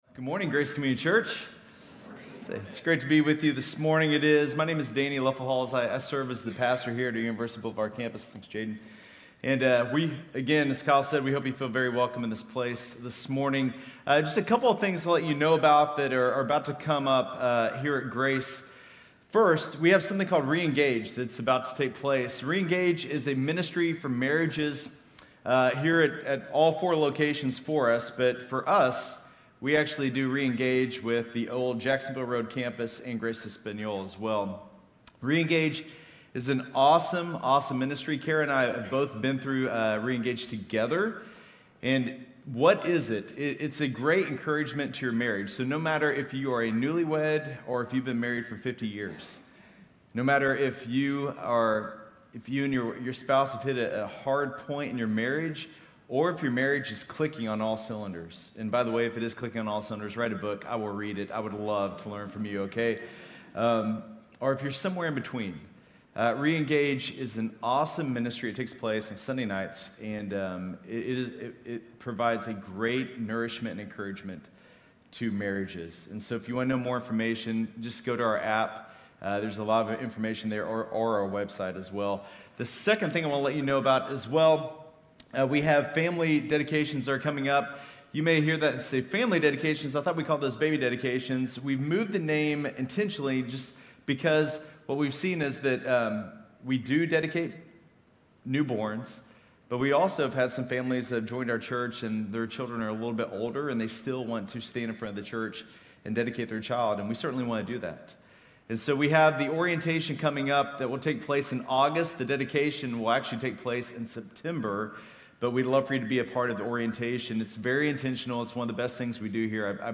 Grace Community Church University Blvd Campus Sermons 1 Timothy 6 Jul 17 2022 | 00:36:30 1x 00:00 / 00:36:30 Subscribe Share RSS Feed Share Link Embed